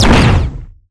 energy_blast_large_02.wav